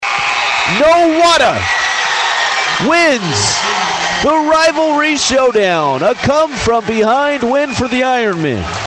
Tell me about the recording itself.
Nowata hosted rival Dewey in a pair of basketball contests, with coverage of the boys game on KRIG 104.9. Here is how the final seconds sounded on KRIG.